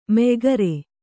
♪ meygare